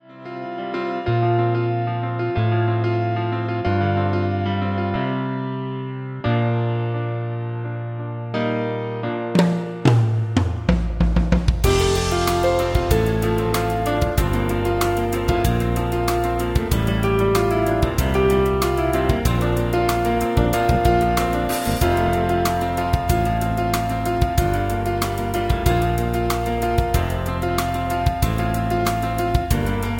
Backing track files: 1970s (954)